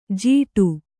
♪ jīguṭṭu